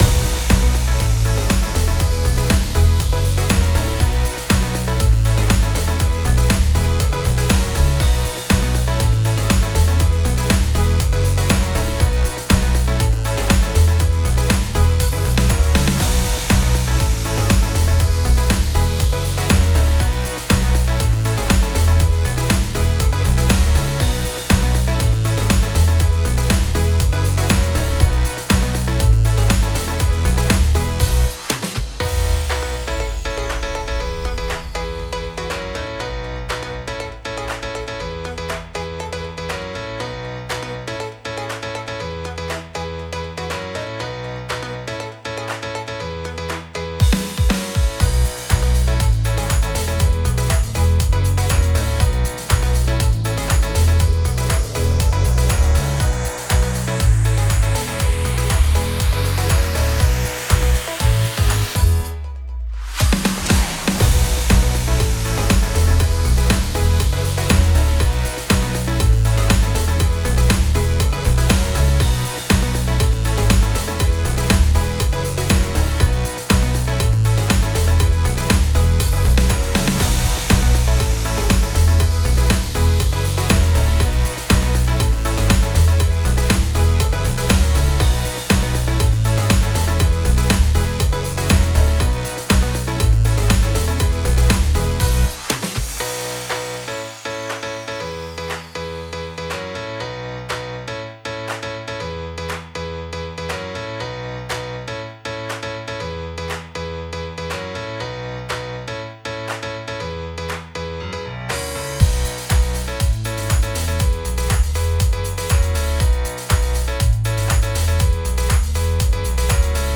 どこか不思議な雰囲気のある、かっこいいゲーム風BGMです
▶ループ対応・メロディなし版